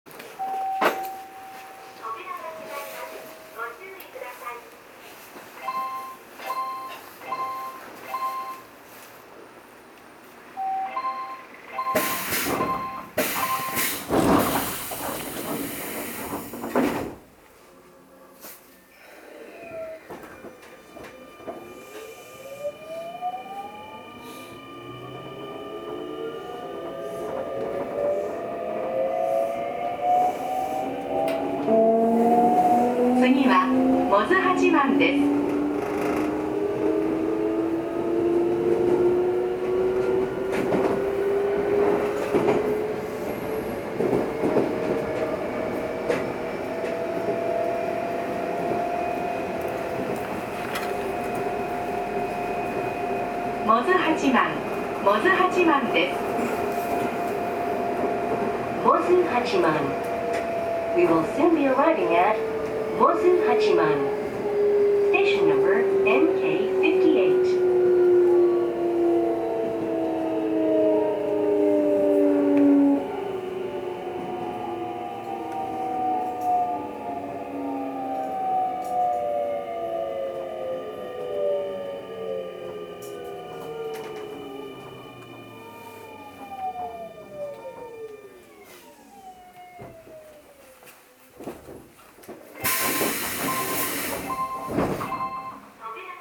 走行機器はGTO素子によるVVVFインバータ制御で、定格170kWのかご形三相誘導電動機を制御します。
走行音
GTO素子車
録音区間：三国ケ丘～百舌鳥八幡(準急)(お持ち帰り)